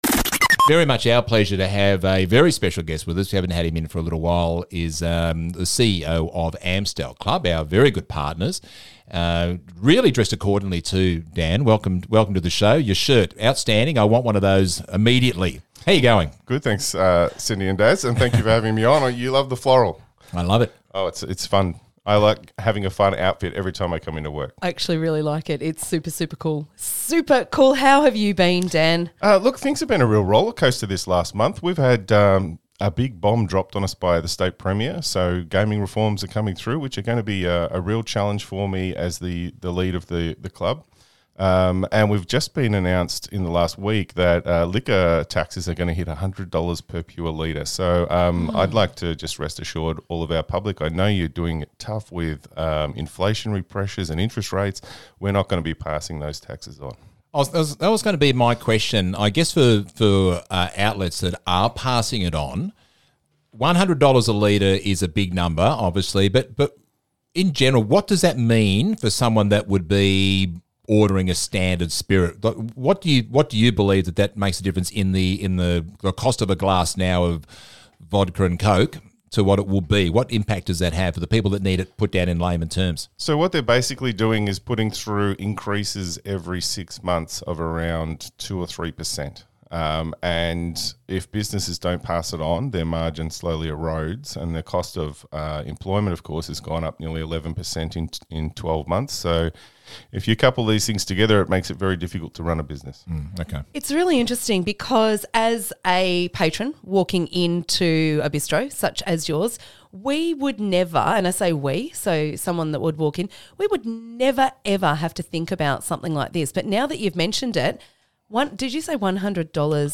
Amstel-Club-Radio-Interview.mp3